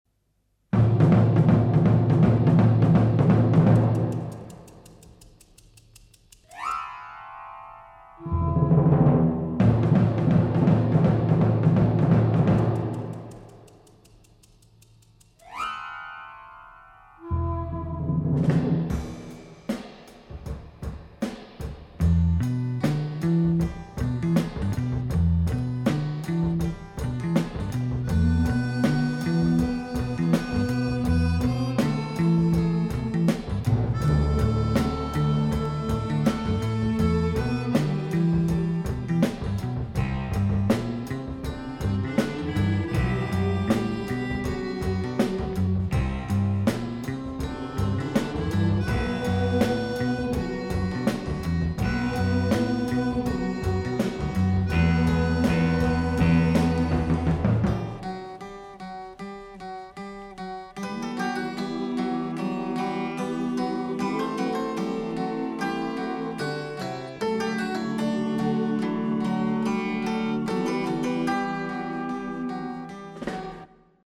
highly original, exotic, rhythmic score
reconstructed from the original 1969 scoring sessions.